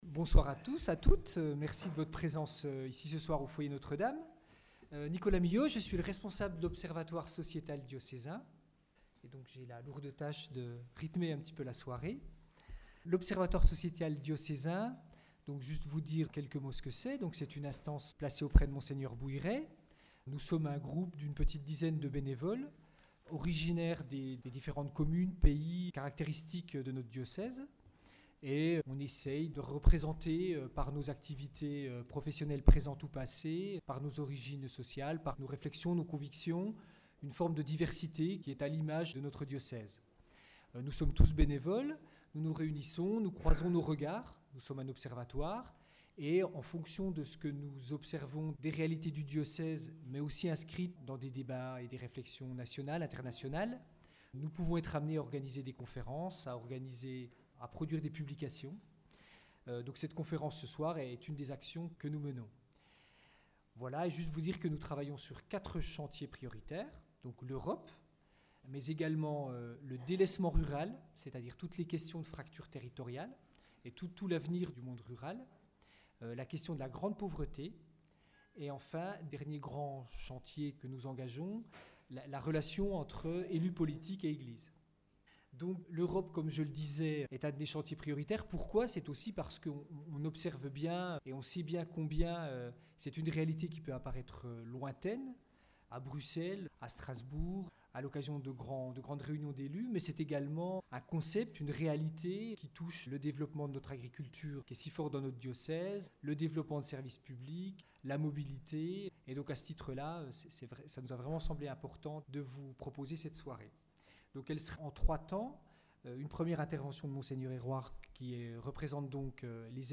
Conférence de Mgr Hérouard